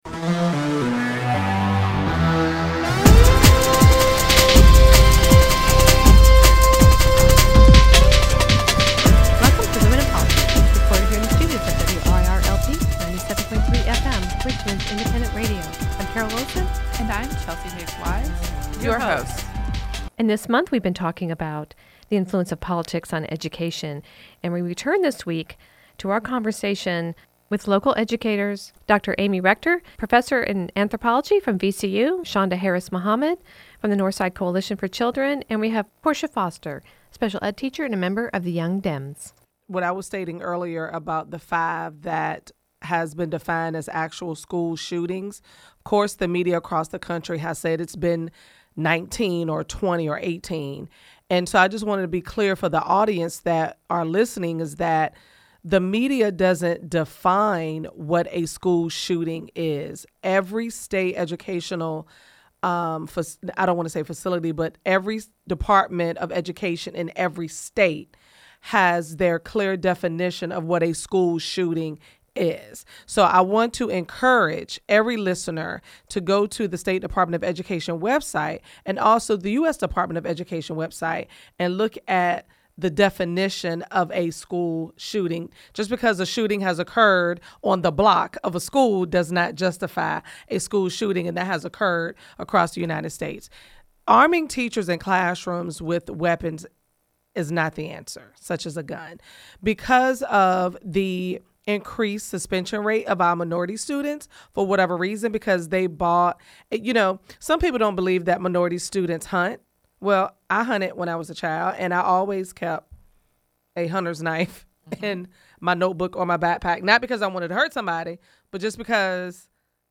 Women and Politics: A discussion with Educators about Guns – Part II